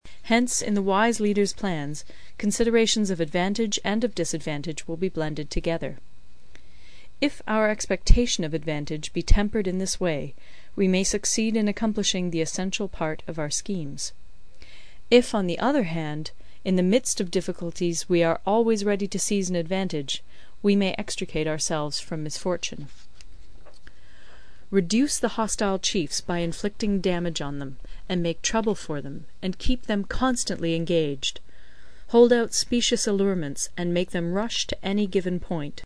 有声读物《孙子兵法》第47期:第八章 九变(3) 听力文件下载—在线英语听力室